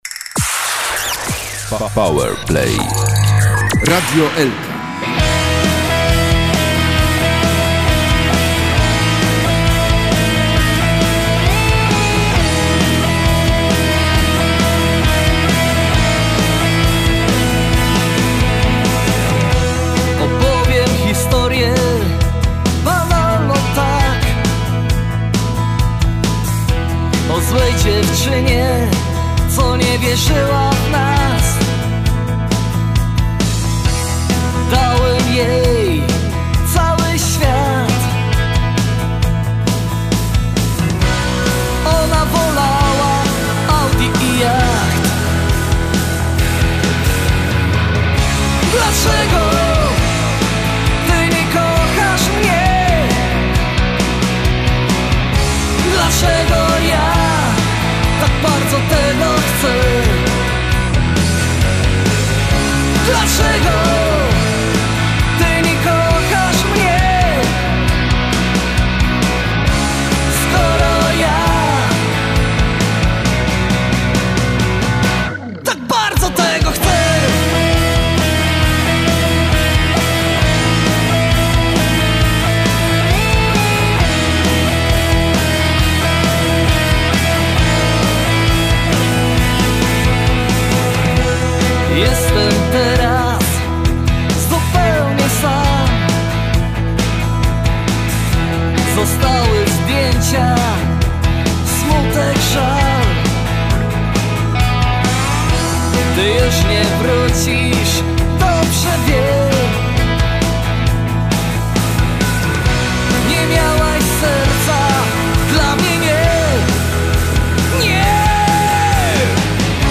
zarejestrowany został w Lesznie
gitara
czyli formację rockową z prawdziwego zdarzenia.
southern rocka
głos
perkusja